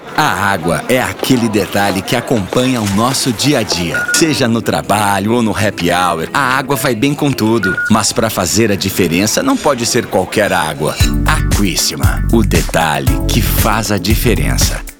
Spot-Aquissima-02-15-seg.wav